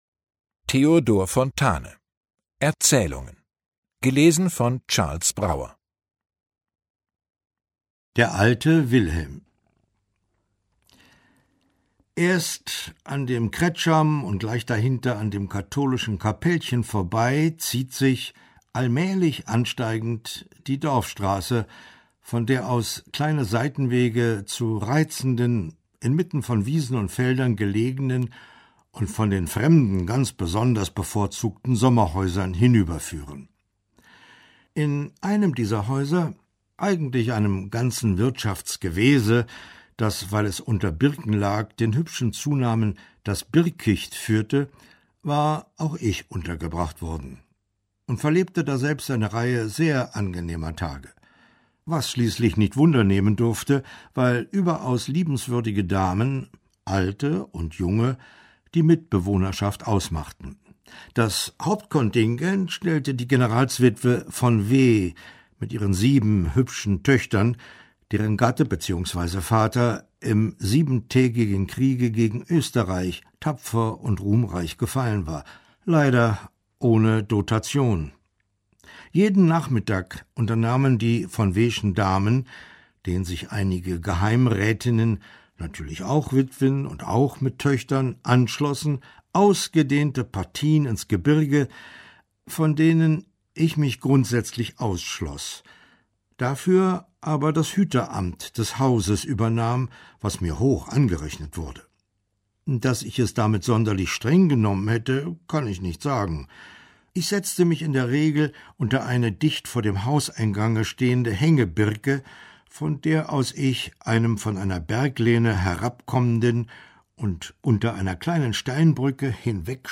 Gekürzte Lesung